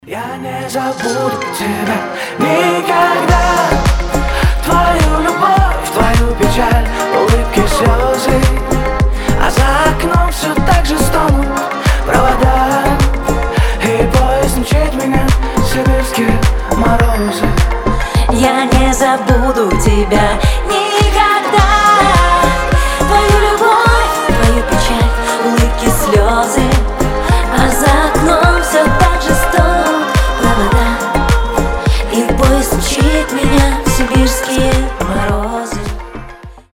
поп
дуэт